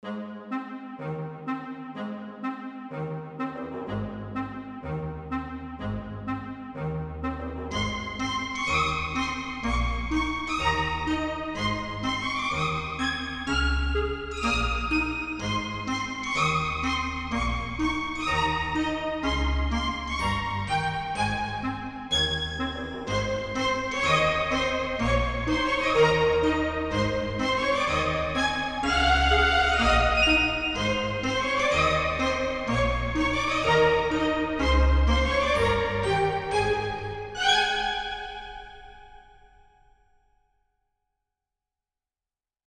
All I did was arrange the tune for a small orchestra xp
Music / Game Music